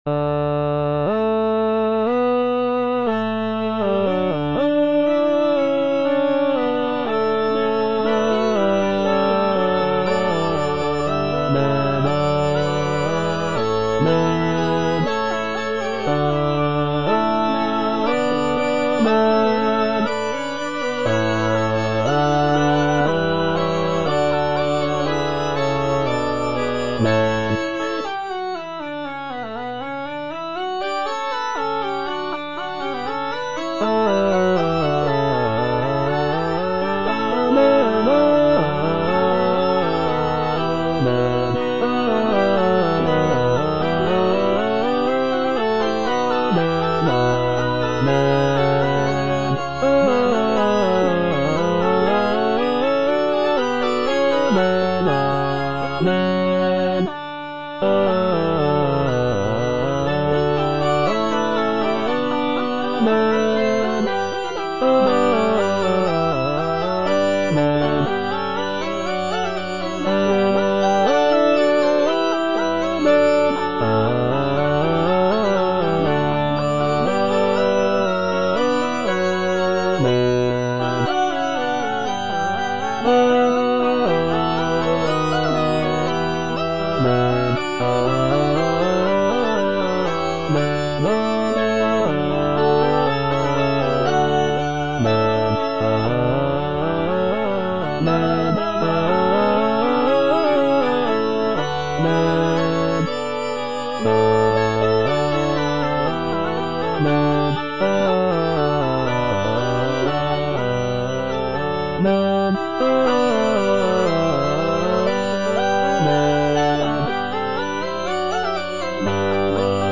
J.D. ZELENKA - MAGNIFICAT IN D MAJOR ZWV108 Amen - Bass (Emphasised voice and other voices) Ads stop: auto-stop
It is based on the biblical text of the Magnificat, a hymn of praise sung by the Virgin Mary. The composition showcases Zelenka's remarkable contrapuntal skills, with intricate vocal lines and rich harmonies.